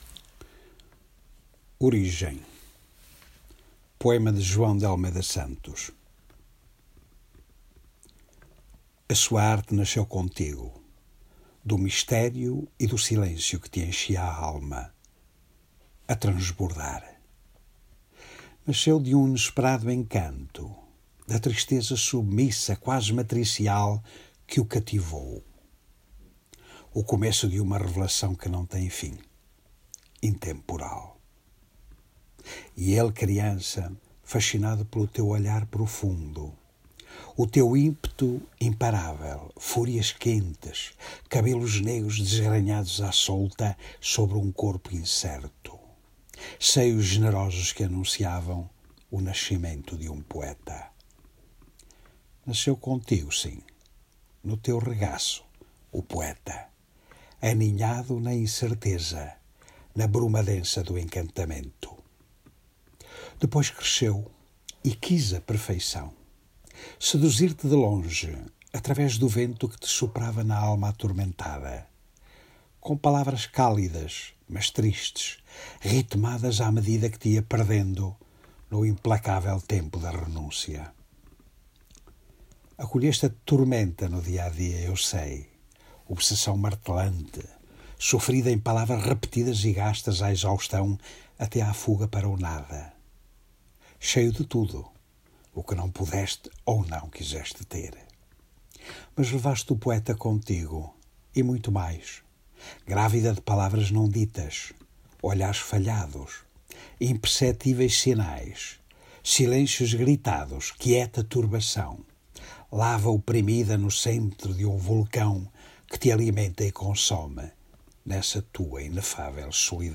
Versão áudio do poema, pelo autor: